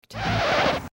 scratch2.mp3